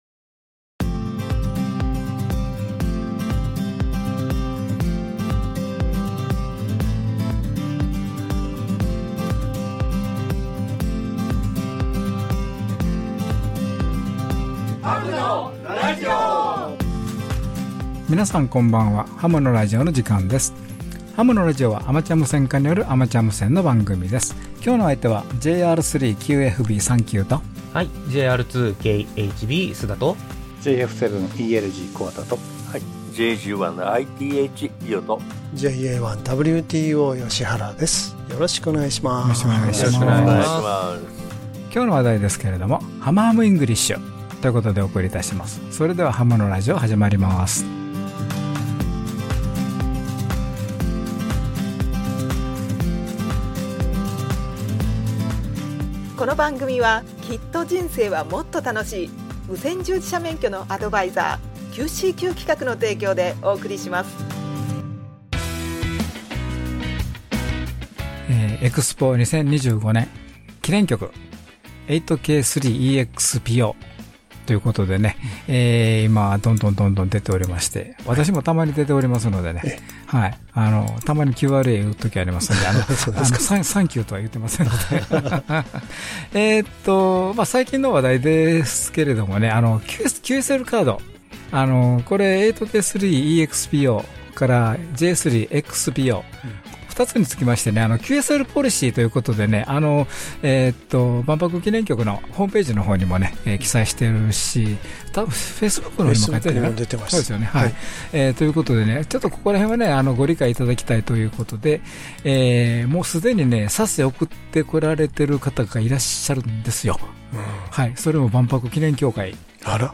ハムのラジオ第644回の配信です。 (2025/5/4 ラジオ成田から放送)